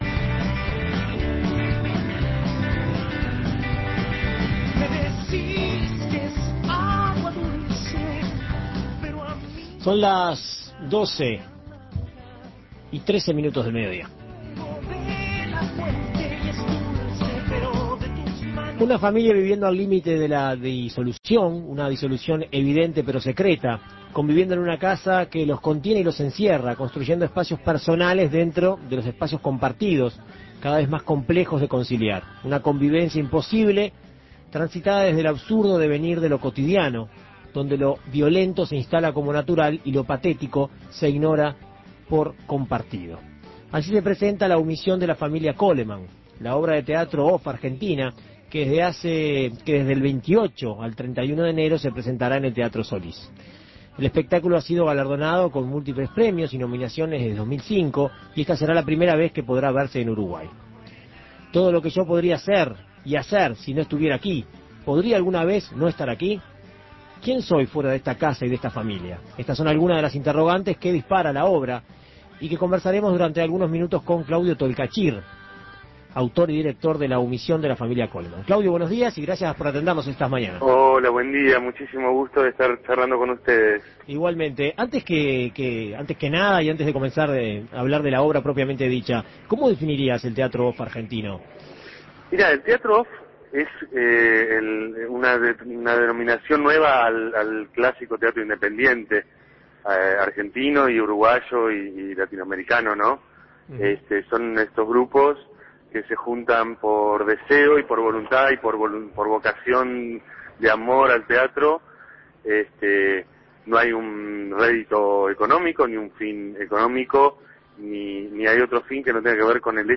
Así se presenta esta obra argentina que se estrena este miércoles en el Solís. Para saber más sobre este espectáculo, En Perspectiva Segunda Mañana dialogó con su autor, Claudio Tolcachir.